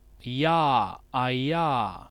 A single-speaker model for KSS based on the CSS10 dataset.
18 j consonant approximant palatal voiced [
palatal_approximant.wav